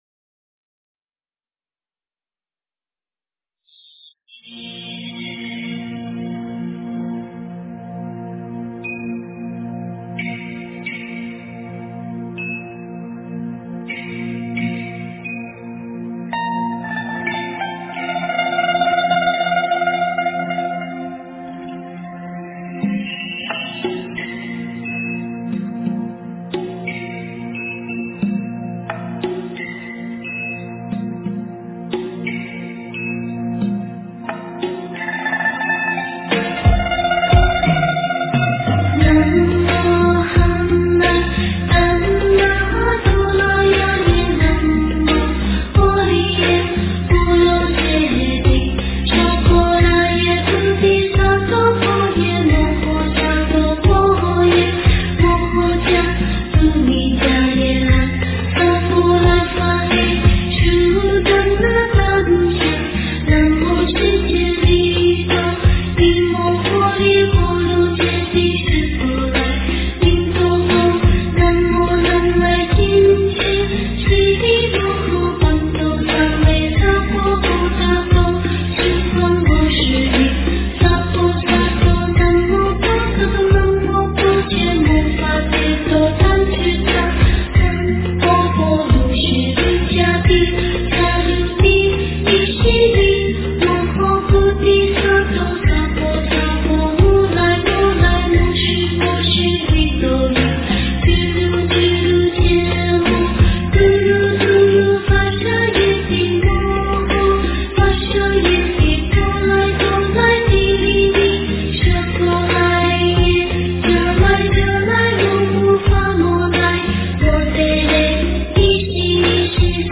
大悲咒 - 诵经 - 云佛论坛
大悲咒 诵经 大悲咒--唱经给你听 点我： 标签: 佛音 诵经 佛教音乐 返回列表 上一篇： 般若波罗蜜多心经-藏文版 下一篇： 大悲咒 相关文章 观音菩萨发愿偈.大悲咒--齐豫 观音菩萨发愿偈.大悲咒--齐豫...